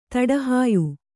♪ taḍahāyu